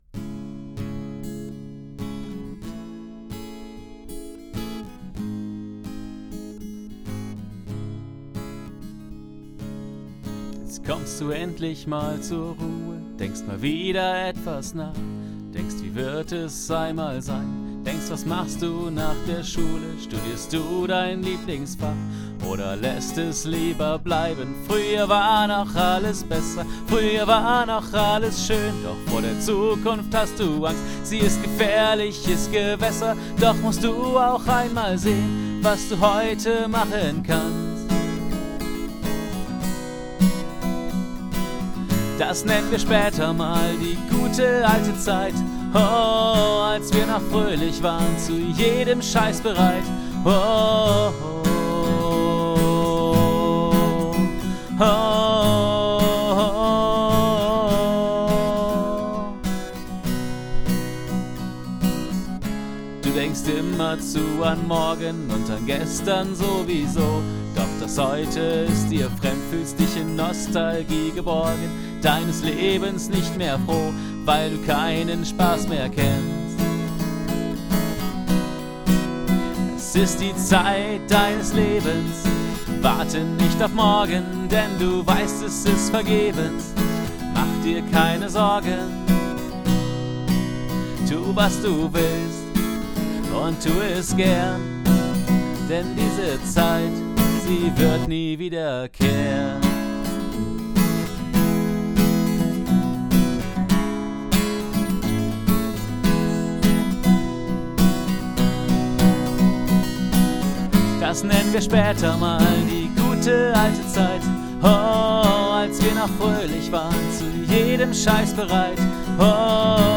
Hier als Akustik-Version (einfache Aufnahme ohne weitere Bearbeitung).